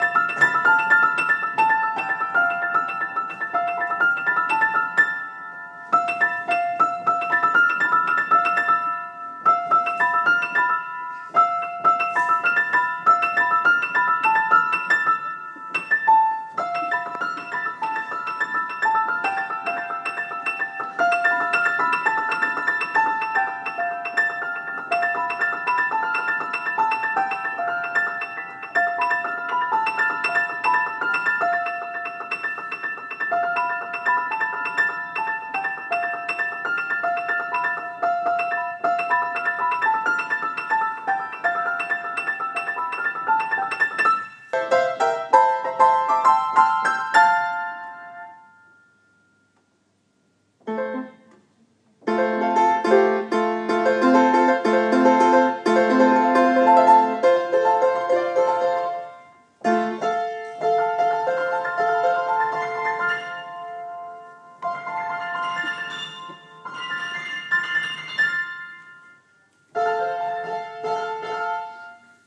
Pianist practising one of the parts to new piece 'Passion' - very hypnotic!